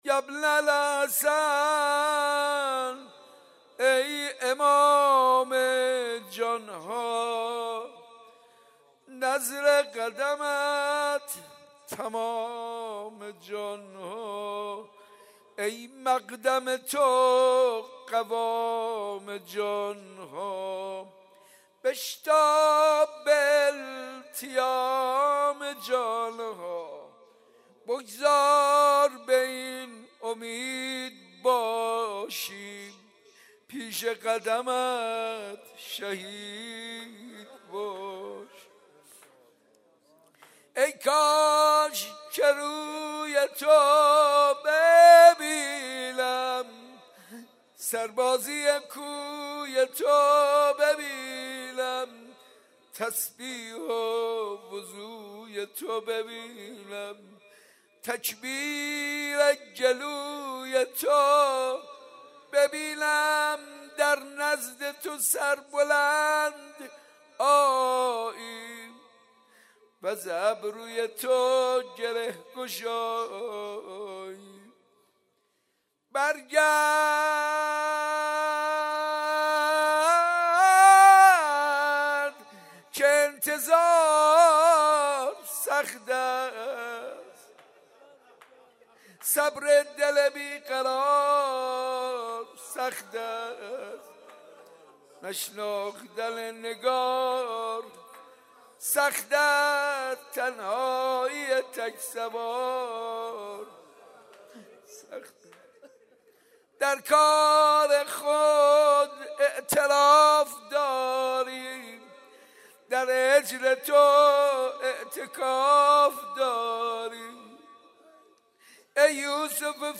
مناجات با امام زمان عجل الله تعالی فرجه الشریف